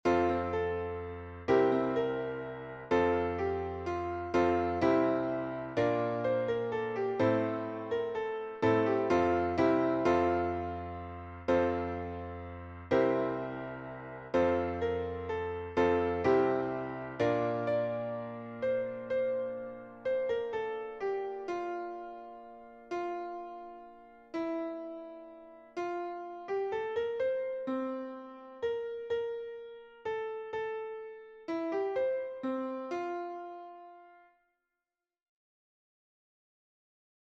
Traditional Canon
(DO-nah NO-beece PAH-chem)
Traditional Latin
Contemplation